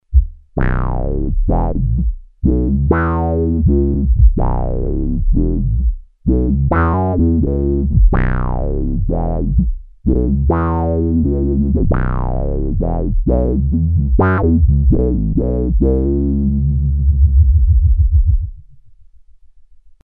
analog bass synth